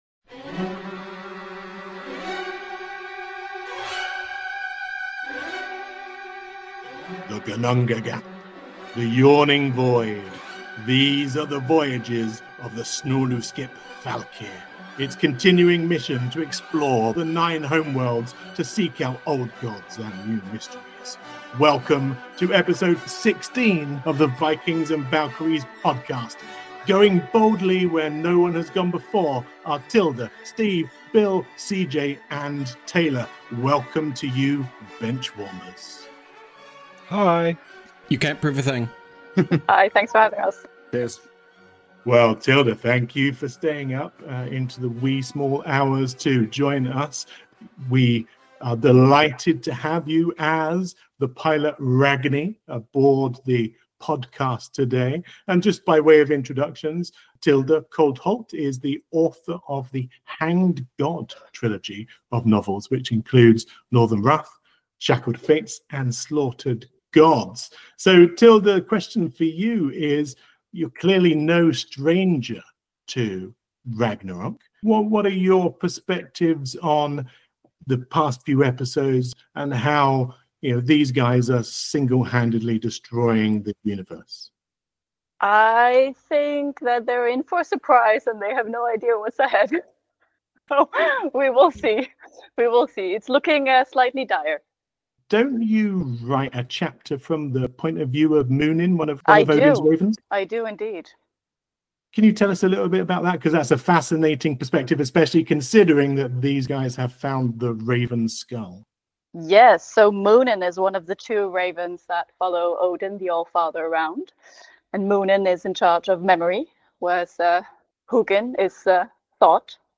Format: Audio RPG
Writing: Improvised Voices: Full cast
Soundscape: Sound effects & music